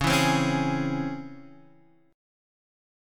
C#mM7 chord